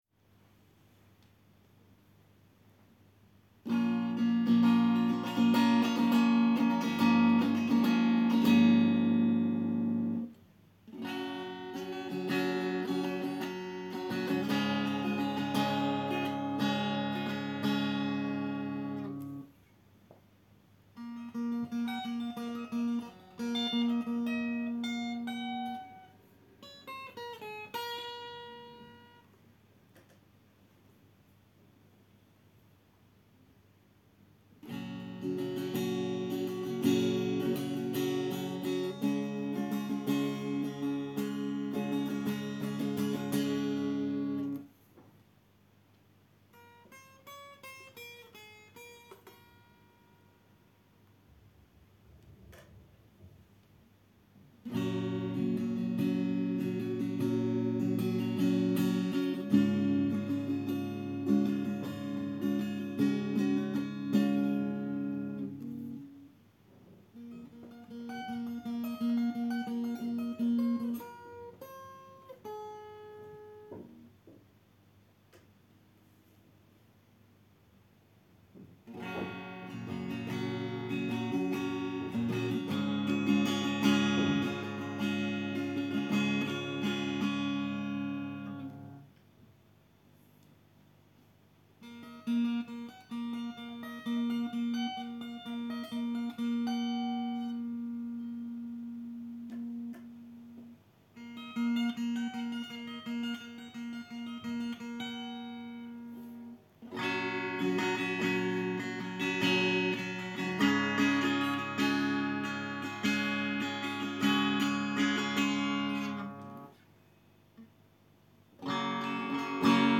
Господа, есть такой варик приобрести электрогитарку: Fender squier affinity Со следующим описанием: гриф-лады почти новые; износ бриджа минимальный...
Спойлер: картинки Вложения Тест Фендер Сквайер.mp3 Тест Фендер Сквайер.mp3 5,4 MB · Просмотры: 1.142